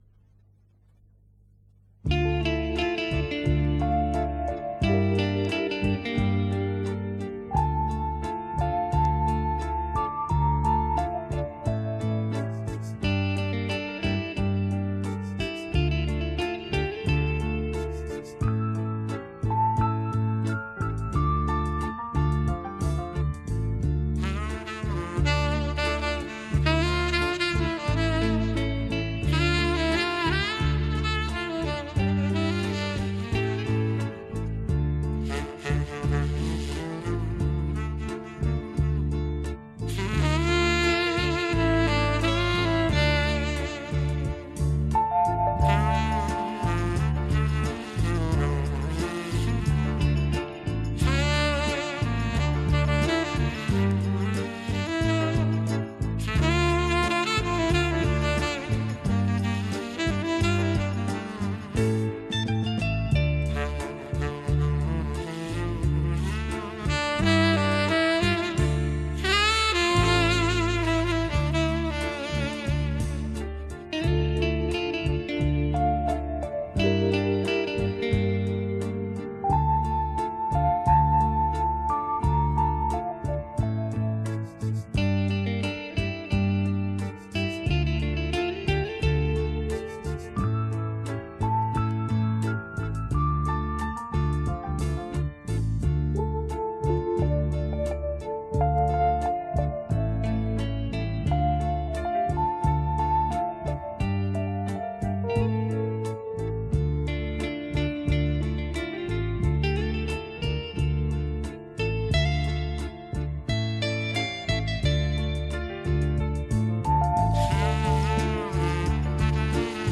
это японский сакс